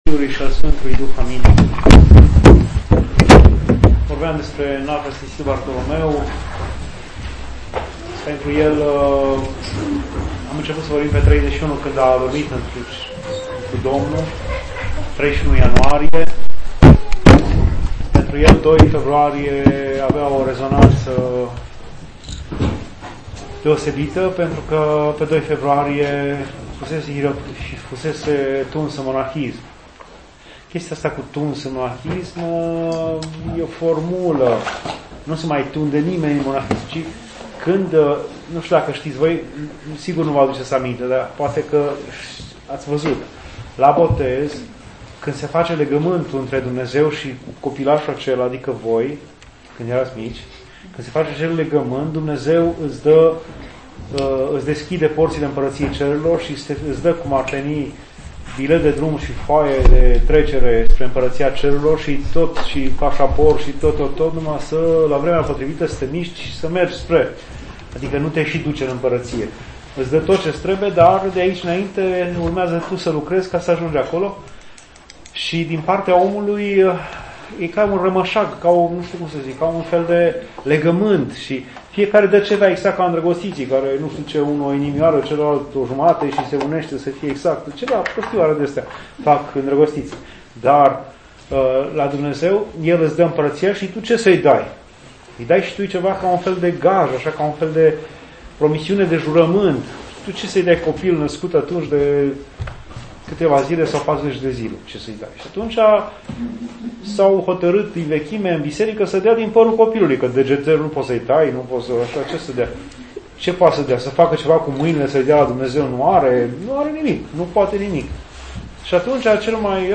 Dumnezeiasca Liturghie